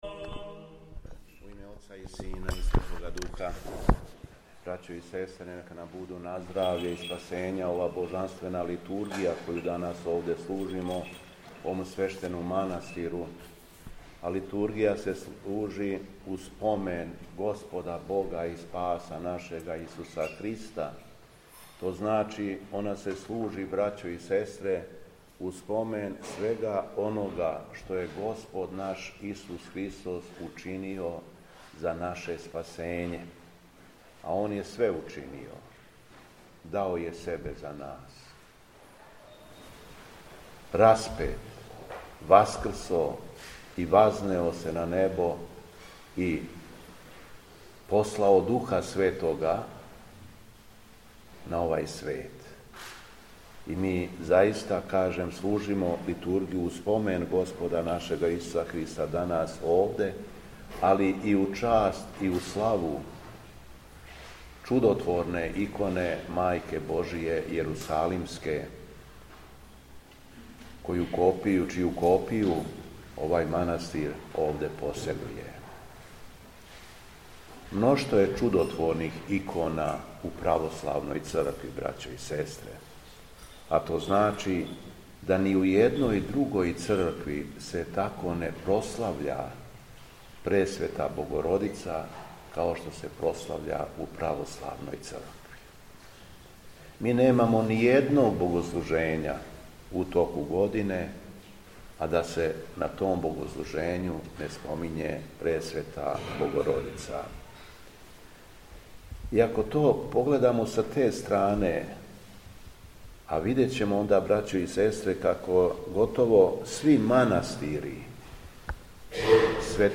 Беседа Његовог Високопреосвештенства Митрополита шумадијског г. Јована
После прочитаног јеванђељског зачала, Митрополит се обратио сабраном народу, рекавши: